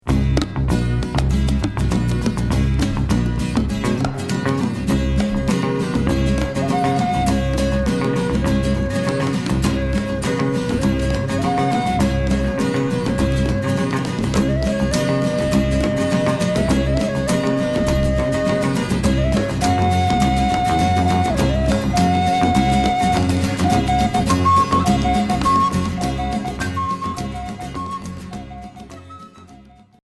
Folk progressif